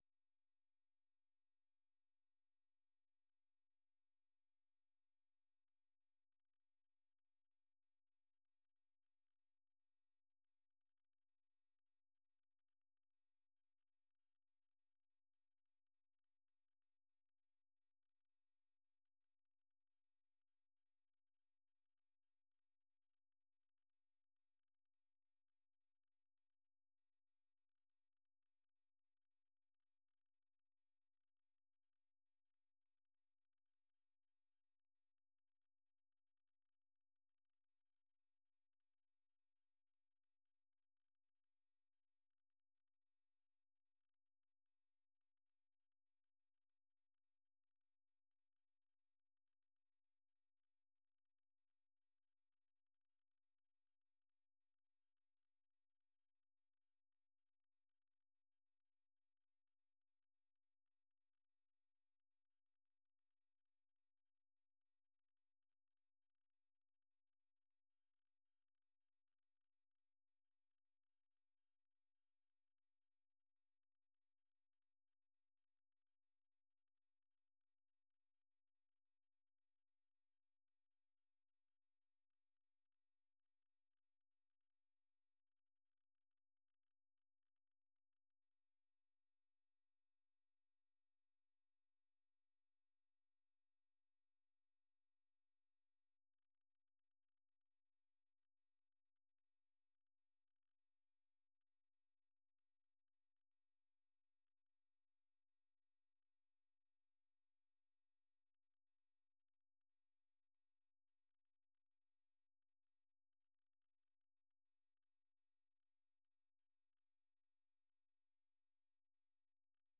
នេះជាកម្មវិធីផ្សាយប្រចាំថ្ងៃតាមវិទ្យុជាភាសាខ្មែរ រយៈពេល ៣០នាទី ដែលផ្តល់ព័ត៌មានអំពីប្រទេសកម្ពុជានិងពិភពលោក ក៏ដូចជាព័ត៌មានពិពណ៌នា ព័ត៌មានអត្ថាធិប្បាយ និងបទវិចារណកថា ជូនដល់អ្នកស្តាប់ភាសាខ្មែរនៅទូទាំងប្រទេសកម្ពុជា។